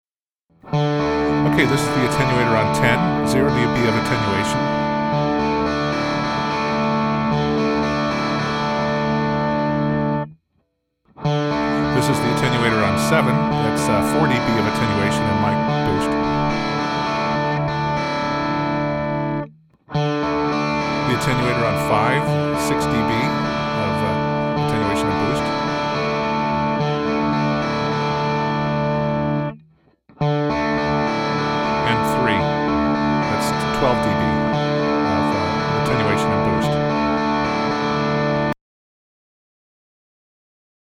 In this second sound file we keep the recorded levels constant at different attenuators settings, making for a simpler tone comparison. We recorded the same Marshall/'63 Strat setup as before, first with the attenuator at full; then at '7', '5', and '3'. Only the Power Attenuator setting and the mic gain were changed so that we could maintain a constant level on the track. The equalization and dynamics were not altered so you can compare the recorded sound at different levels of attenuation.
Attenuator at 10, 7, 5, and 3 (Listen)